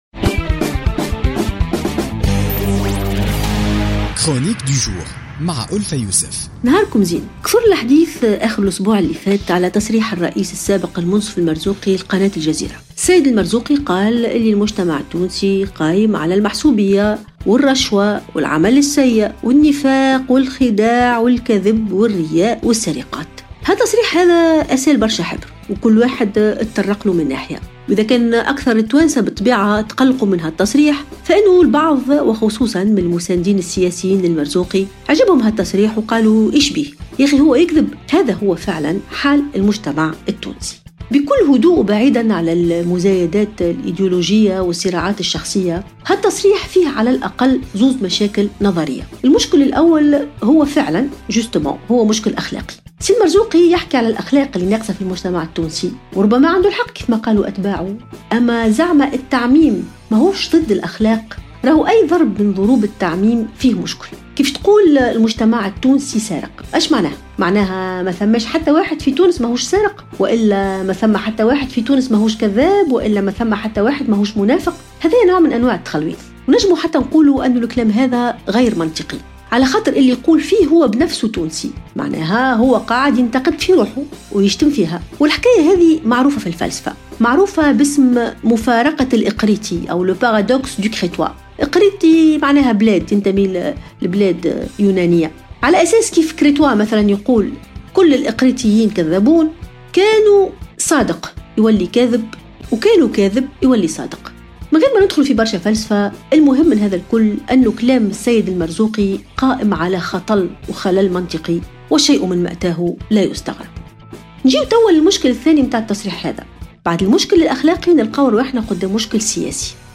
تطرقت الكاتبة ألفة يوسف في افتتاحية اليوم الثلاثاء 14 فيفري 2017 إلى تصريحات الرئيس السابق على قناة الجزيرة التي قال فيها أن الشعب التونسي منافق ومرتشي والعلاقات فيه قائمة على المحسوبية والخداع والسرقات.